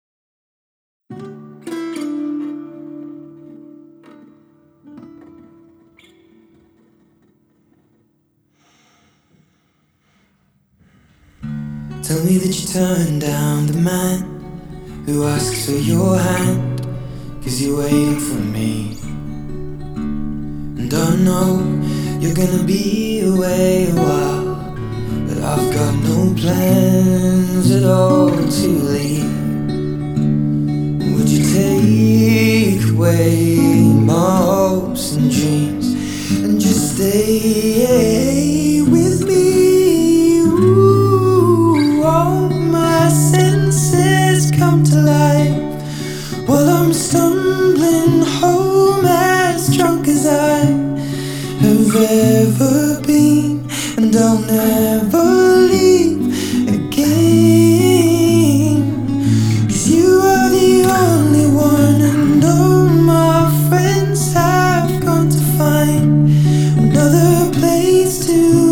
Processed version with the ancient theatre filter,